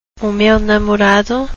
oo mayu namorahdu – lit. ‘the my boyfriend’